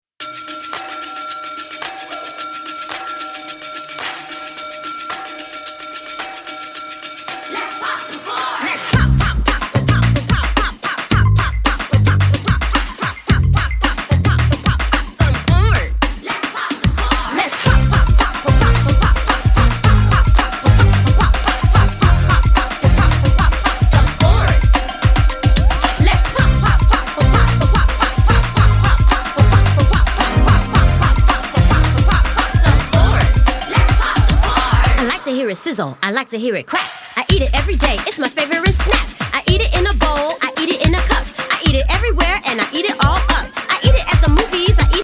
is an ode to the classic rap style of the 80's
Upbeat and interactive
over a soundboard of jazz, gospel and soulful - pop sounds.